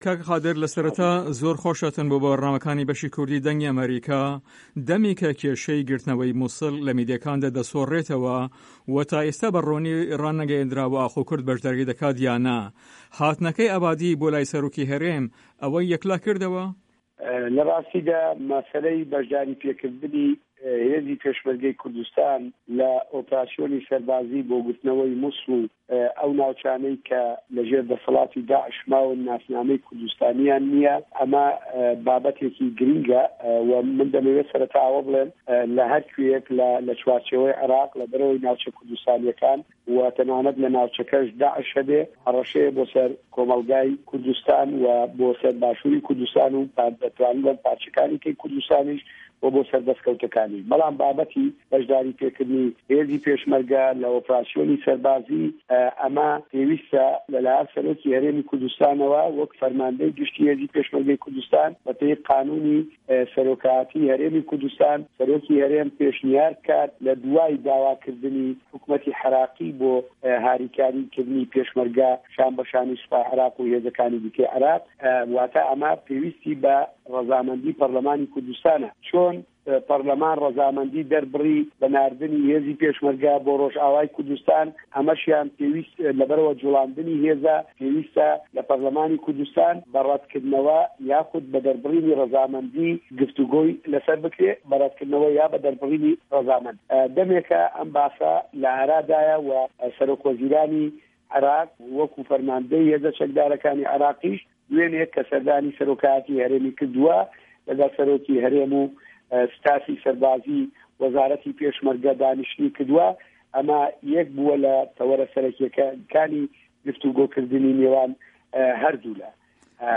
قادر ره‌زگه‌یی ئه‌ندامی کۆمیته‌ی پێشمه‌رگه‌ و شه‌هیدان له‌ په‌رله‌مانی کوردستان له‌ هه‌ڤپه‌یڤینێکدا له‌گه‌ڵ به‌شی کوردی ده‌نگی ئه‌مه‌ریکا ده‌ڵێت" له‌ هه‌ر کوییه‌ک له‌ چوارچێوه‌ی عێراق، له‌به‌ر ئه‌وه‌ی ناوچه‌ کوردستانیه‌کان وه‌ ته‌نانه‌ت داعش هه‌بێ هه‌ره‌شه‌یه‌ بۆ سه‌ر کۆمه‌ڵگای کوردستان وه‌ بۆ سه‌ر باشوری کوردستان و پارچه‌کانی دیکه‌ی کوردستانیش وه‌ بۆ سه‌ر ده‌سکه‌وته‌کانی، با‌به‌تی به‌شداریکردنی هێزی پێشمه‌رگه‌ له‌ ئۆپه‌راسیۆنی هێزی سه‌ربازی‌ پێوه‌یسته‌ له‌ لایه‌ن سه‌رۆکی هه‌رێمی کوردستانه‌وه‌ وه‌ک فه‌رمانده‌ی گشتی هێزی پێشمه‌رگه‌ی کوردستان به‌ پێی یاسای سه‌رۆکایه‌تی هه‌رێمی کوردستان، له‌ دووای داواکردنی حکومه‌تی عێراقی بۆ هاریکردنی پێشمه‌رگه‌ شان به‌ شانی سوپای عێراق و هێزه‌کانی دیکه،‌ سه‌رۆکی هه‌رێم پێشنیار بکات بۆ به‌شداریکردن وه‌ ئه‌مه‌ پێویستی به‌ ره‌زامه‌ندی په‌رله‌مانی هه‌رێمی کوردستانه‌".